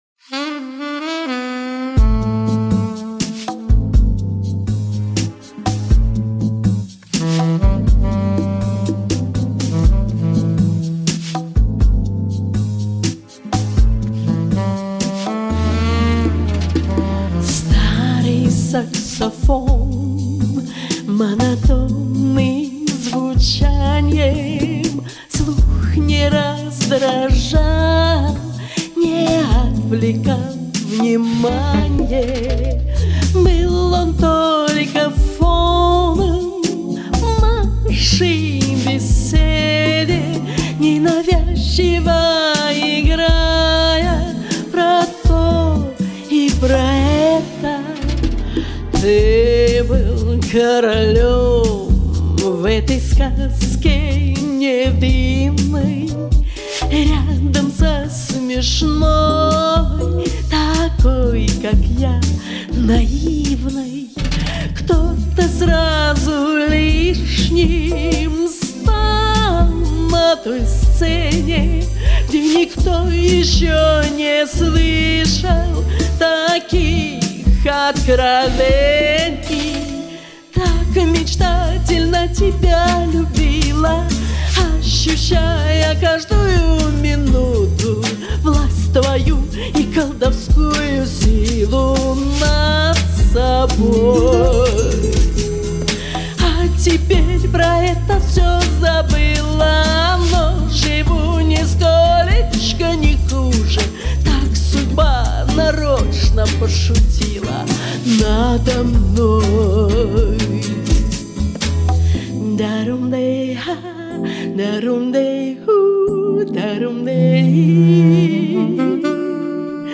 «BLUES на русском»
Блюз.......